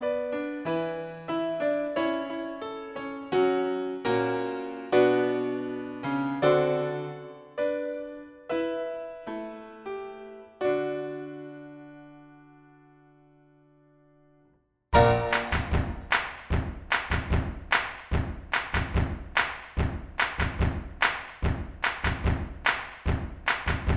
No Vocals At All Soundtracks 3:18 Buy £1.50